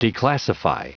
Prononciation du mot declassify en anglais (fichier audio)
Prononciation du mot : declassify